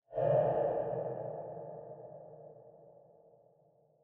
Voidsfx.mp3